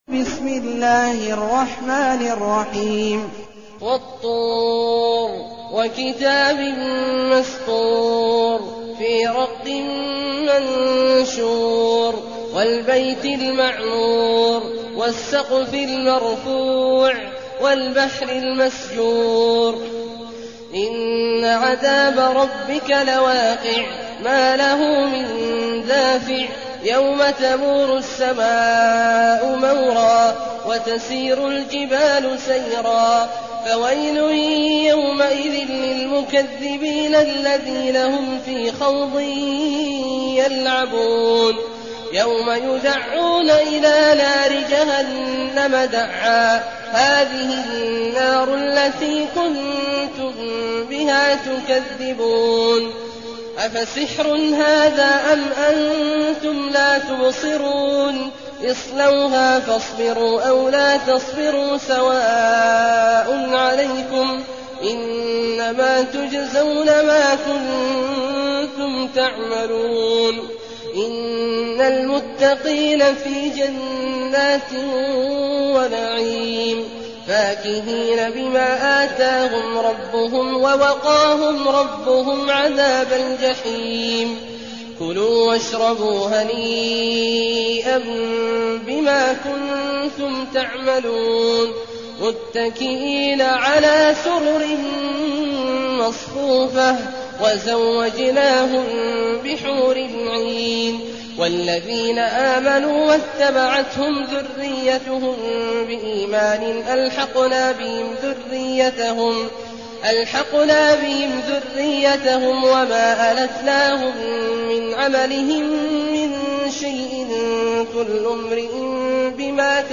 المكان: المسجد النبوي الشيخ: فضيلة الشيخ عبدالله الجهني فضيلة الشيخ عبدالله الجهني الطور